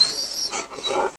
wolf-die-1.ogg